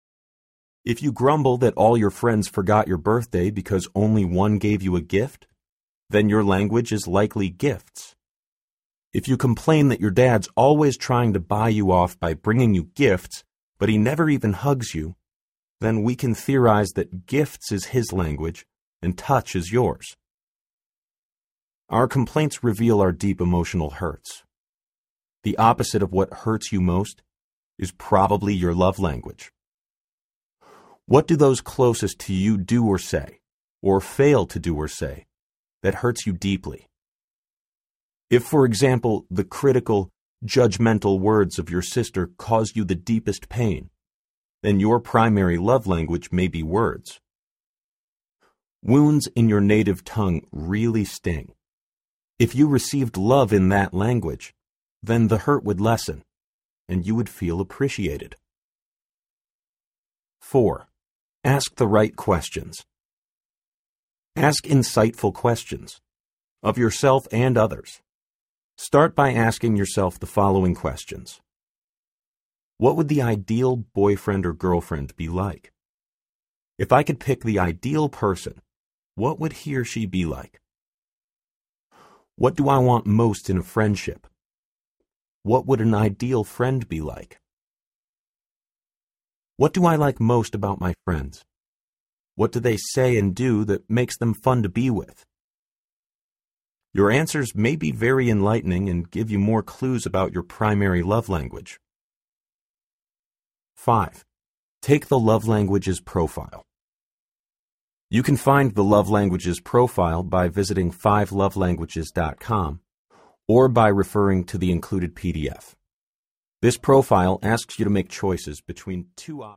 A Teen’s Guide to the 5 Love Languages Audiobook
3.6 Hrs. – Unabridged